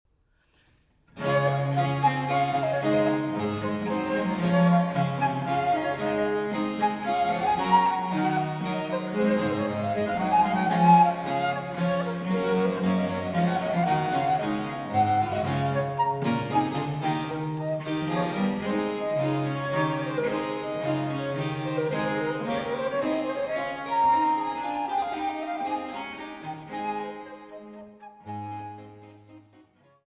Affetuoso